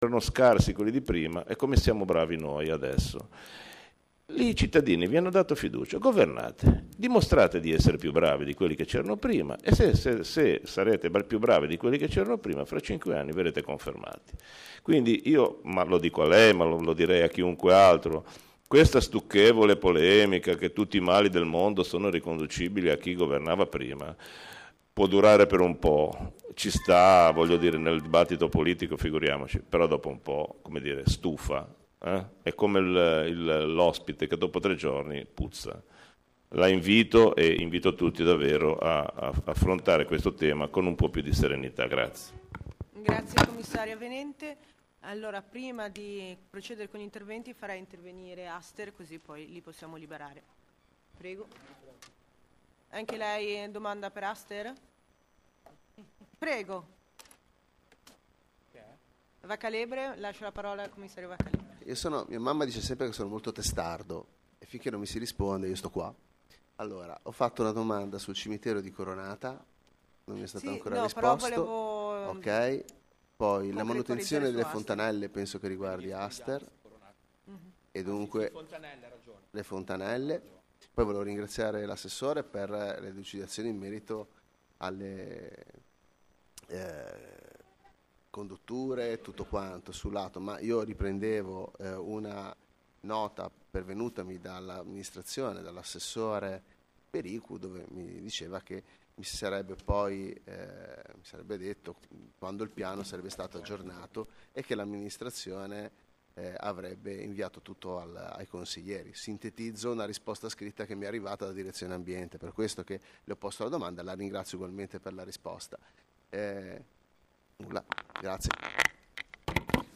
Commissione Consiliare III V di martedi 09 dicembre 2025 ore 0900 Pt1.mp3